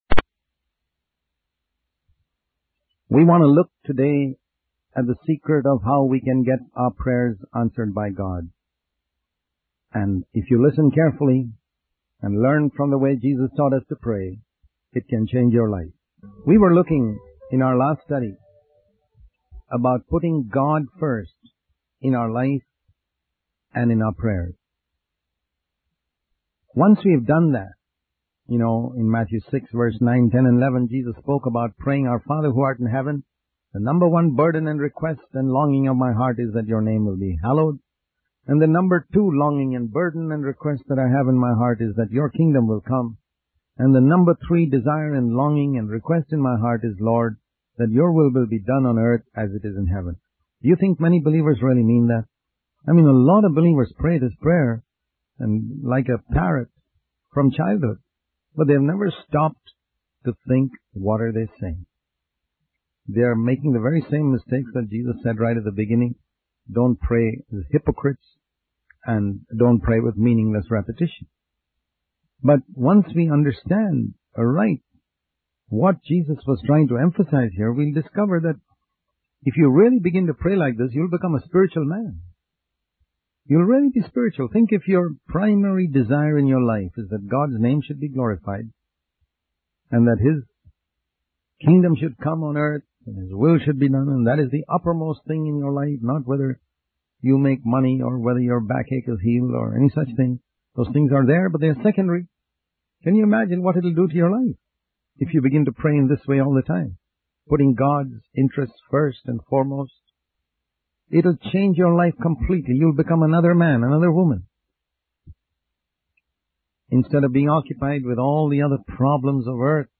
In this sermon, the speaker emphasizes the importance of putting God first in our lives and prayers.